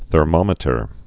(thər-mŏmĭ-tər)